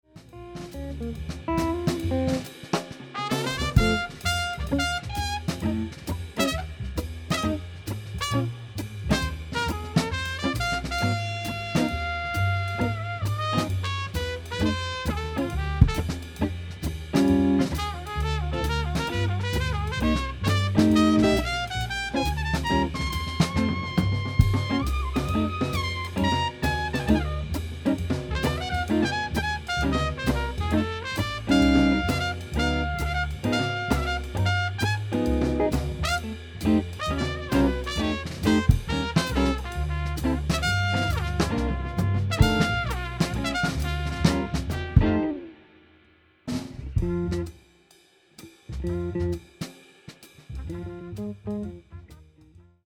(en quartet : trompette, guitare, contrebasse, batterie) :
Medium -